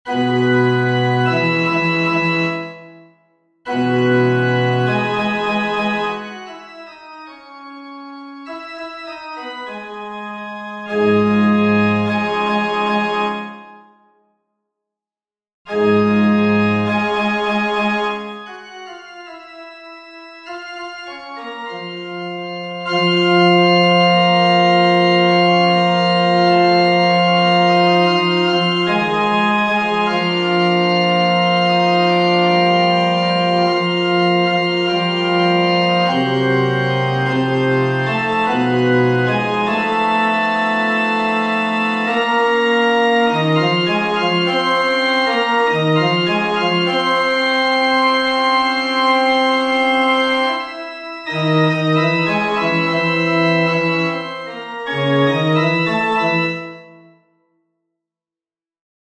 FF:HV_15b Collegium male choir
Pensistum-bas.mp3